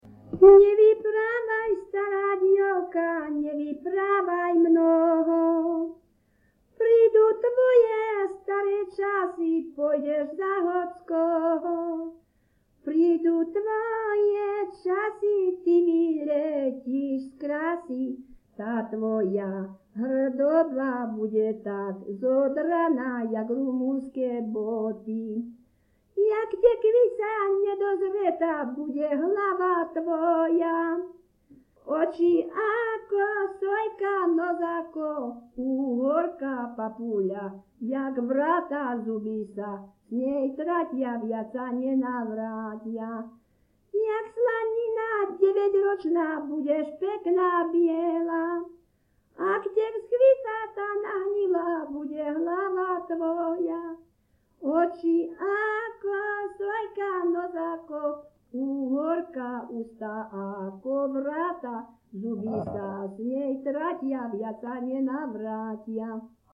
Popis sólo ženský spev bez hudobného sprievodu
Miesto záznamu Litava
Kľúčové slová ľudová pieseň